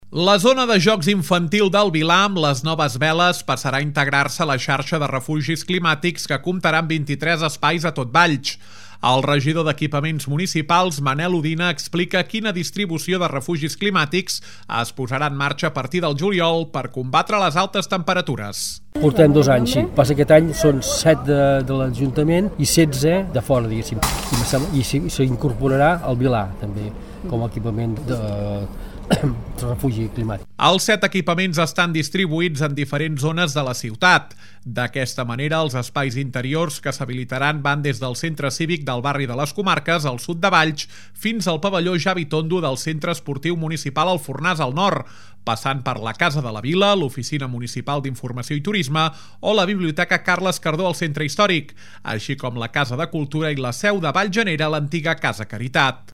El regidor d’Equipaments Municipals, Manel Odina, explica quina distribució de refugis climàtics es posarà en marxa a partir del juliol per combatre les altes temperatures.